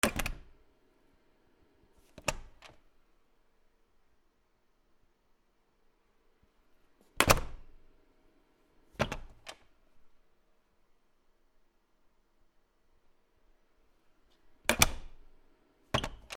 扉
/ K｜フォーリー(開閉) / K05 ｜ドア(扉)
『カチャ』